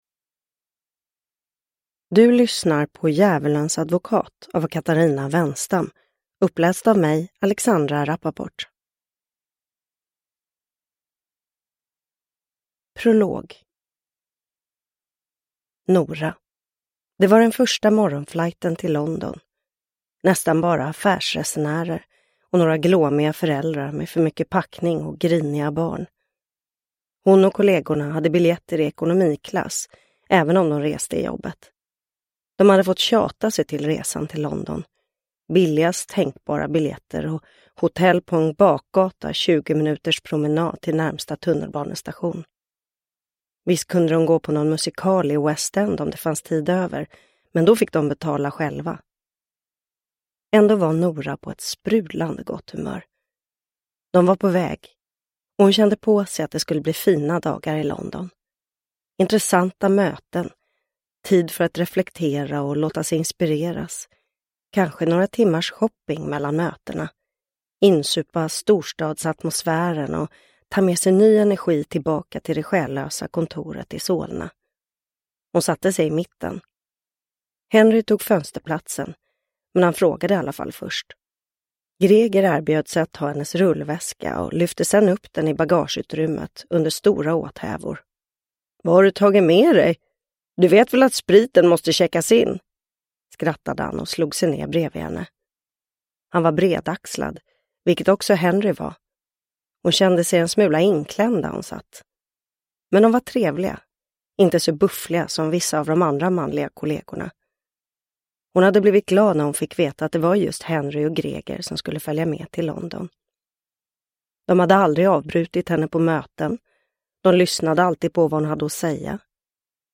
Djävulens advokat – Ljudbok – Laddas ner
Uppläsare: Alexandra Rapaport